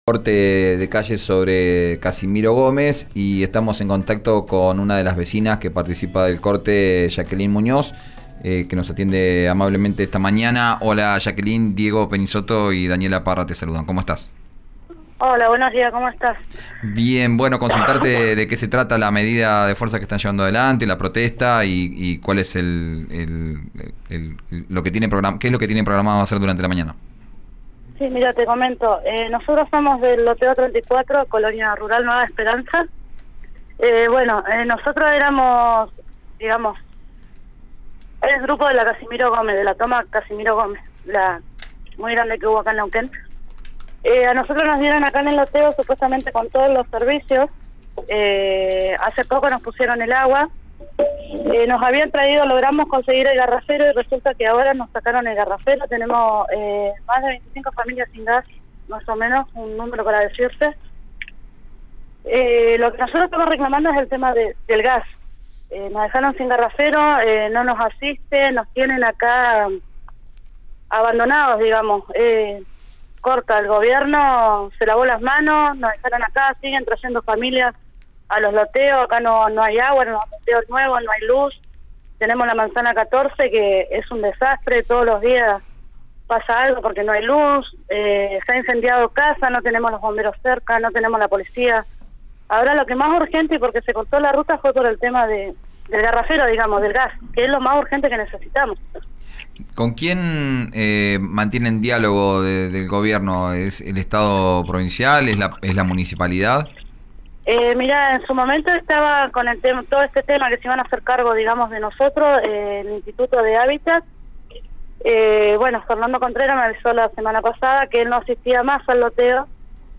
Sintonizá RÍO NEGRO RADIO.